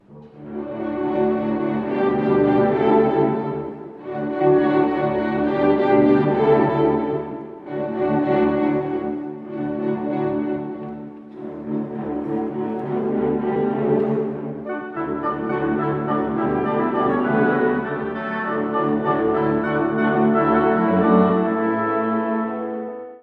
↑古い音源なので聴きづらいかもしれません！（以下同様）
弦のさざ波のようなD-durの和声に乗って、気取らない木管のメロディーで幕を開けます。
交響曲第1番の寒々しさとは違い、すこし明るい自然を思わせるようです。